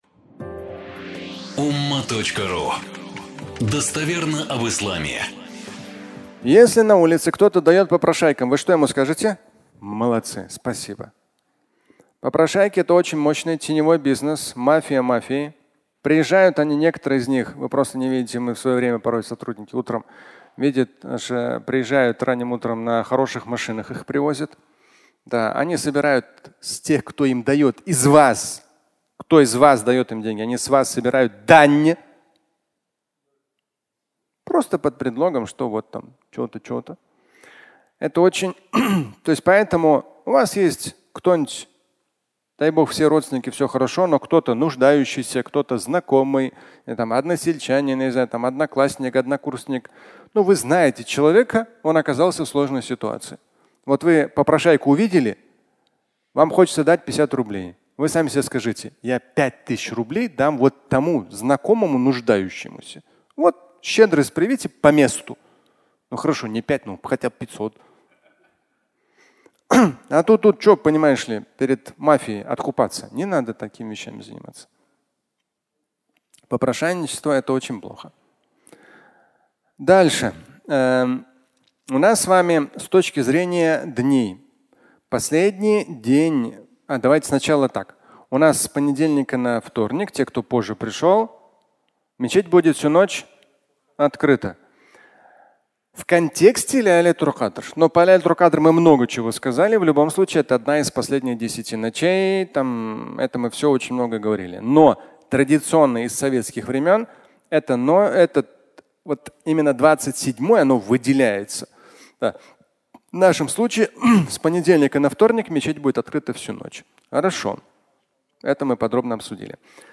Объявления (аудиолекция)